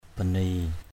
/ba˨˩-ni:˨˩/